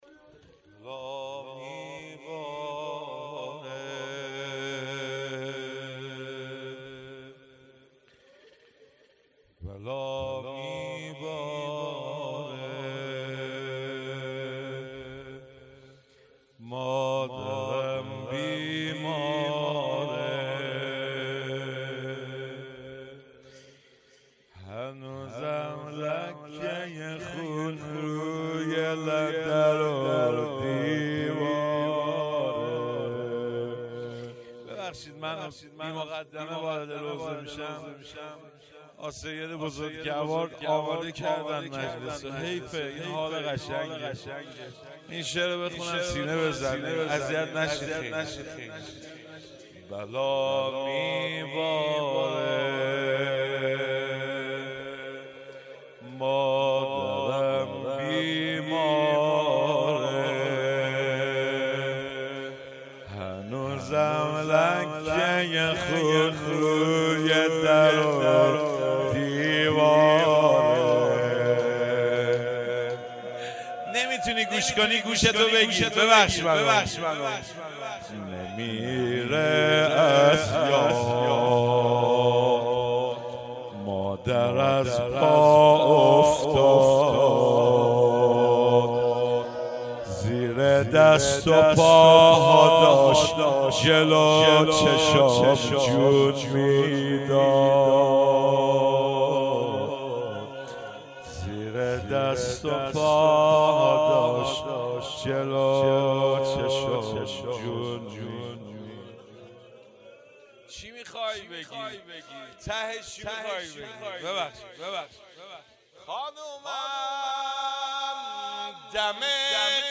روضه
هیئت عقیله بنی هاشم سبزوار